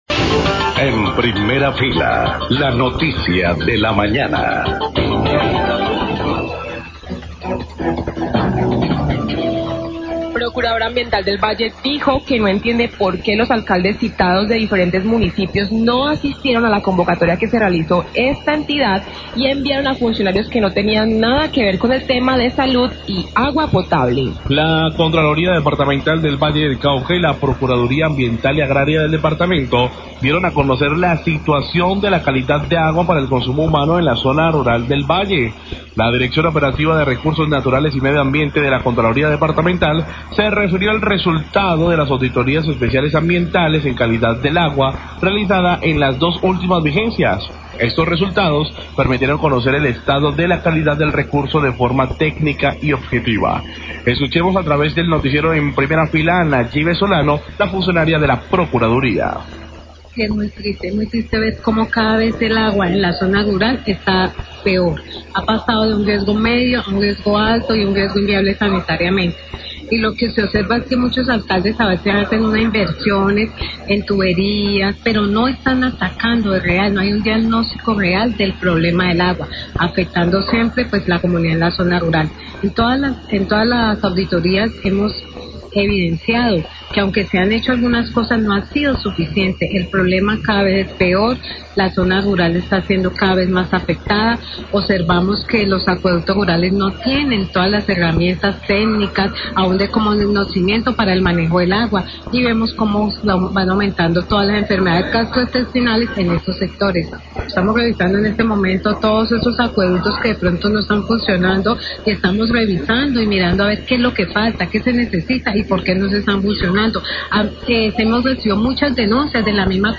LA PROCURADORA AMBIENTAL DEL VALLE HABLA SOBRE LA CALIDAD DEL AGUA EN LA ZONA RURAL, ROBLES, 6 15AM
Radio